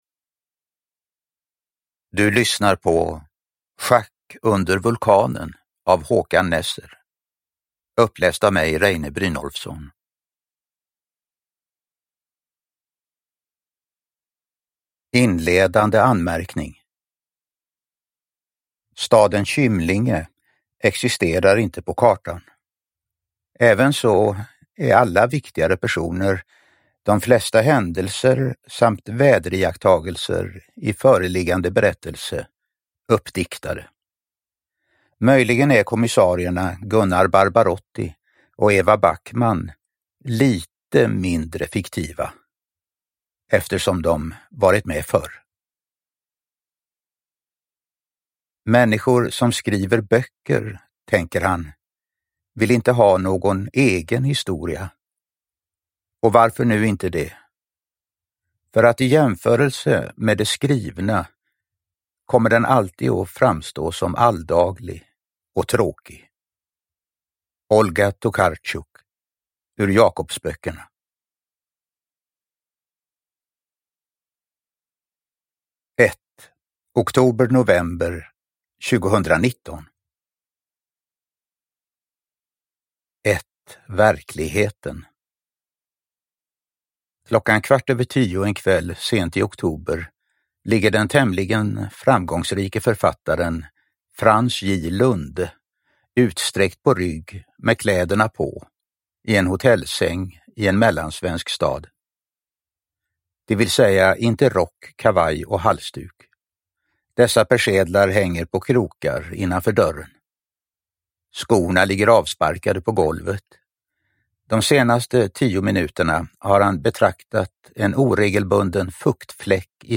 Schack under vulkanen – Ljudbok – Laddas ner
Uppläsare: Reine Brynolfsson